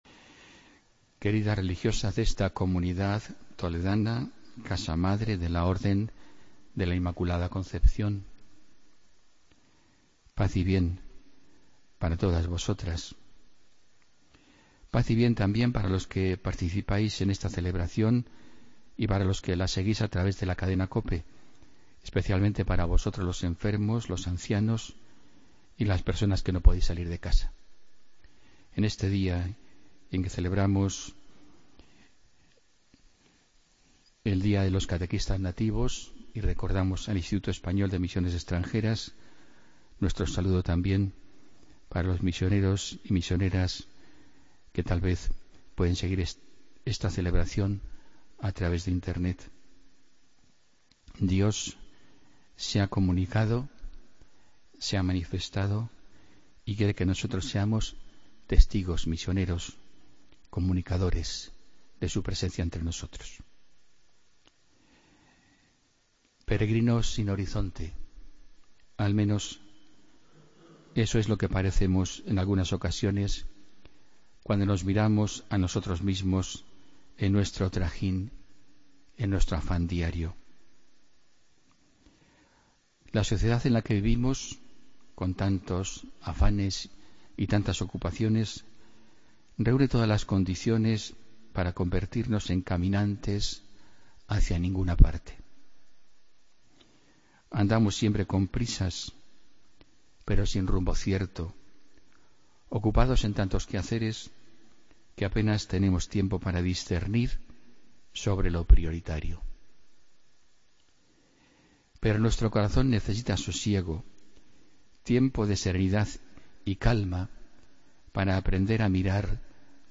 Homilía del viernes 6 de enero de 2017